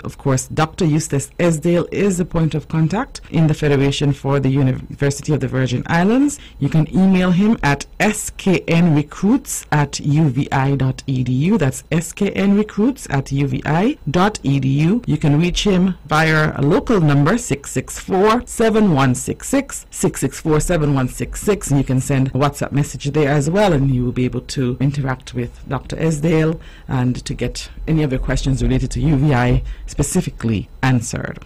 Here is Permanent Secretary in the Ministry of Education-Nevis, Ms. Zahnela Claxton: